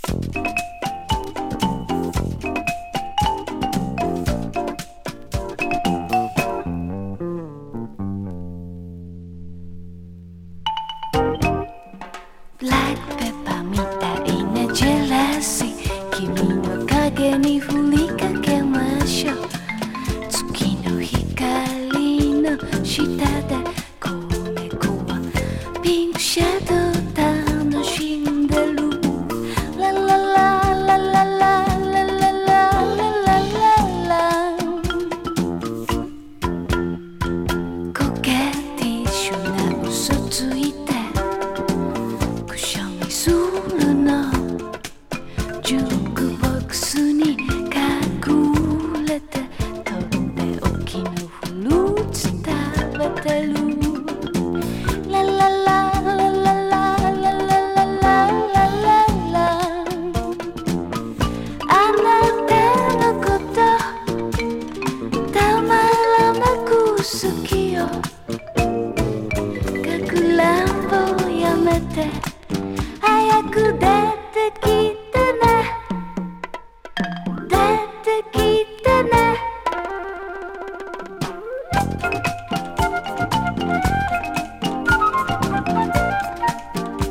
キュートでコミカルなタイトル曲オススメ。